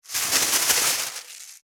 599スーパーの袋,袋,買い出しの音,ゴミ出しの音,袋を運ぶ音,
効果音